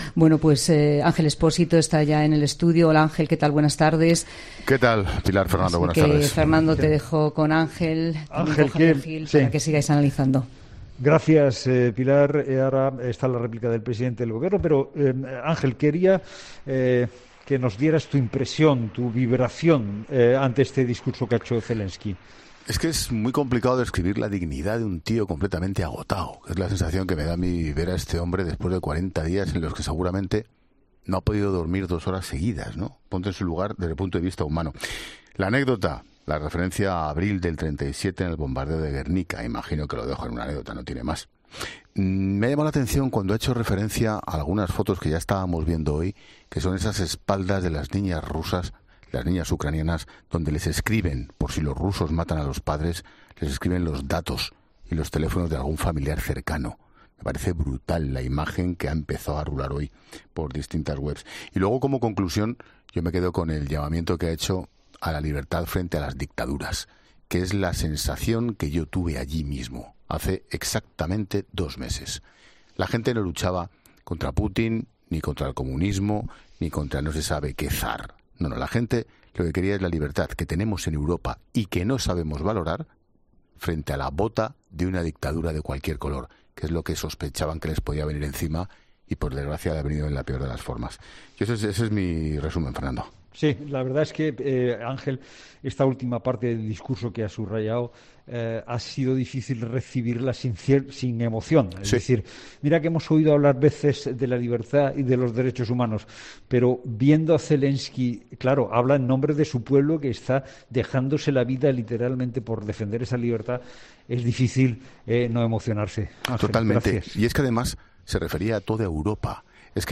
El director de 'La Linterna', Ángel Expósito, interviene en 'La Tarde' y reflexiona tras la comparecencia de Zelenski en el Hemiciclo